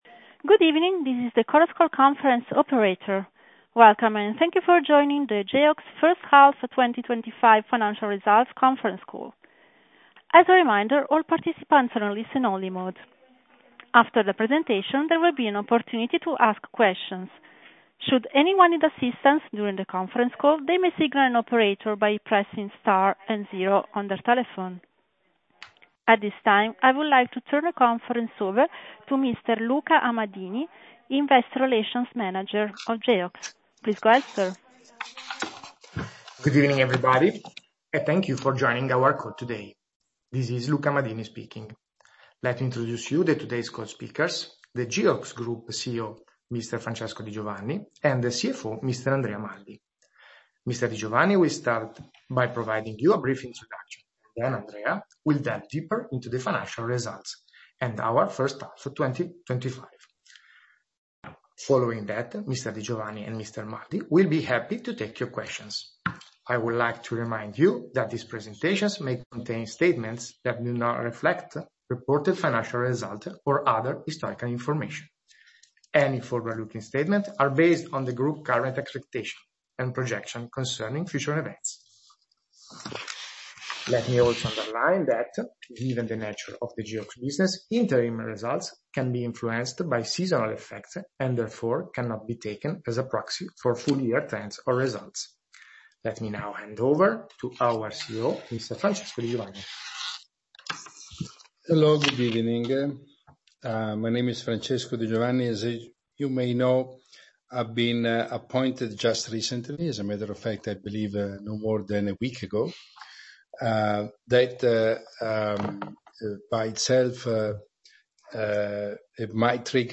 Conference Call - Presentation 9M25 Financial Results